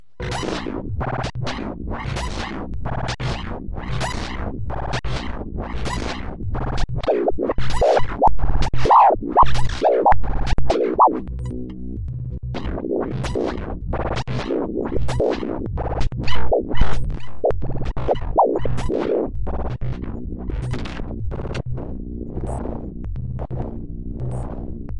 描述：抽象毛刺效应
标签： 科幻 奇怪 音响设计 效果 设计 毛刺 随机 摘要
声道立体声